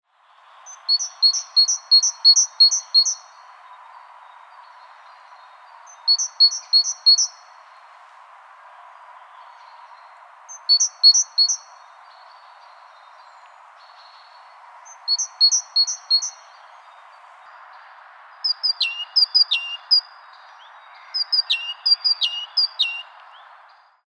rasvatihane-eoy.ogg